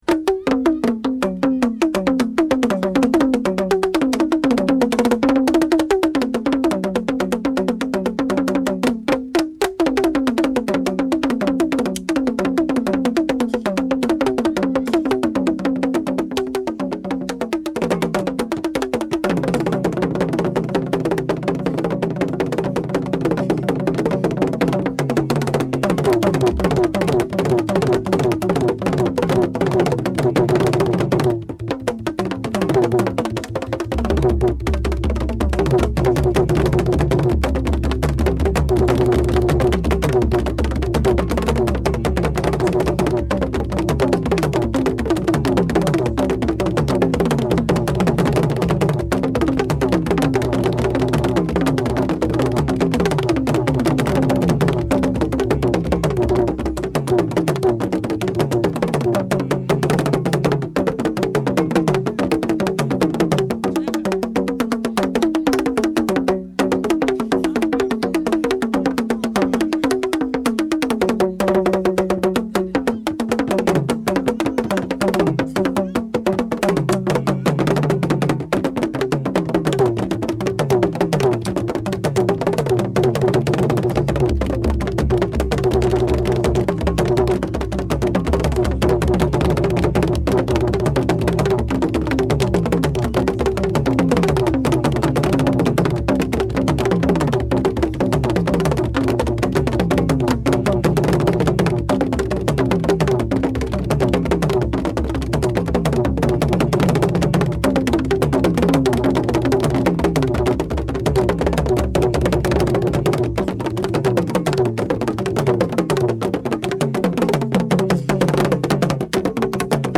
Likhuba TR089-12.mp3 of Likhuba (Drum Rhythm)